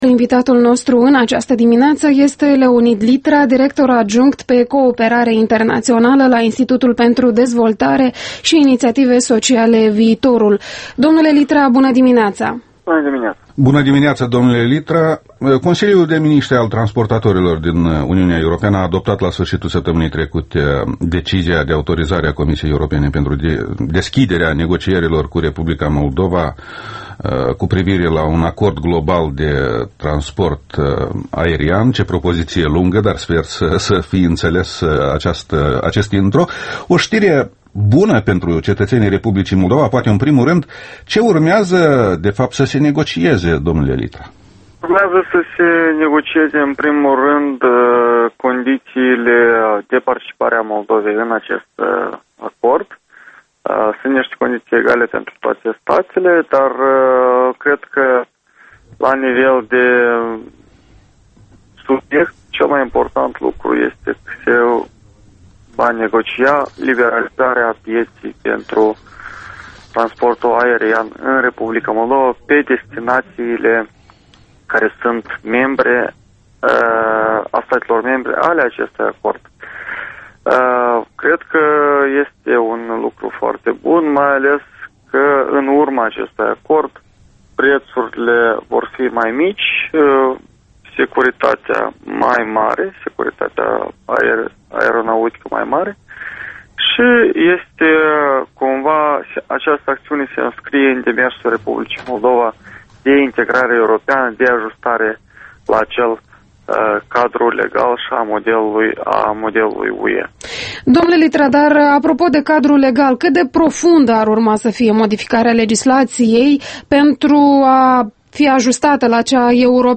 Interviul matinal EL: